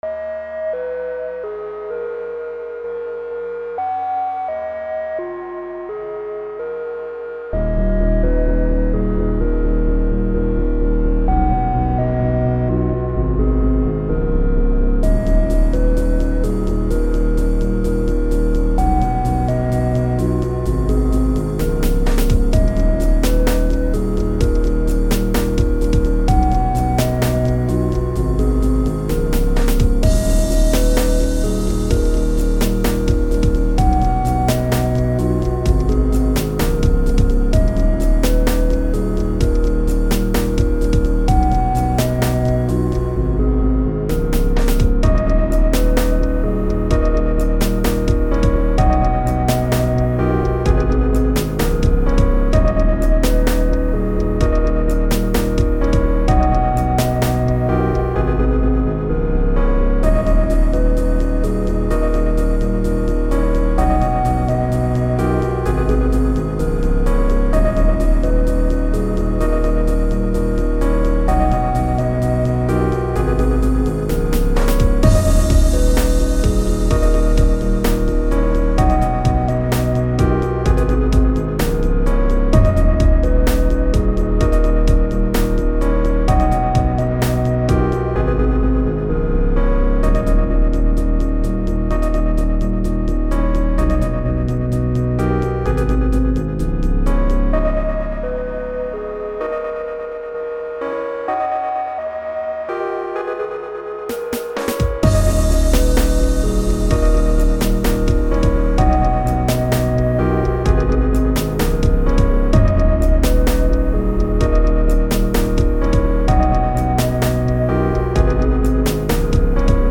Playing around with the Harmonic Minor key. And some real mellow instruments, to create this really off beat and broken track, like a warped record.